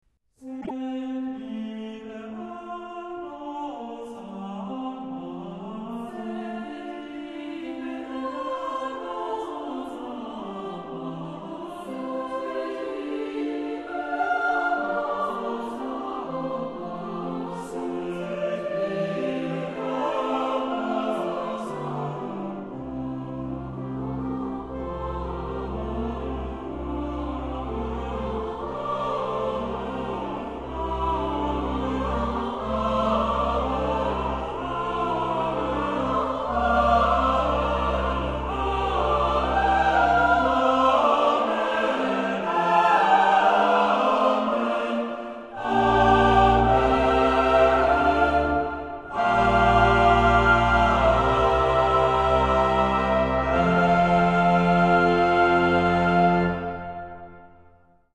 Genre-Style-Forme : Sacré ; Motet ; Romantique ; Prière
Type de choeur : SATB  (4 voix mixtes )
Instruments : Orgue (1) ou Piano (1)
Tonalité : fa majeur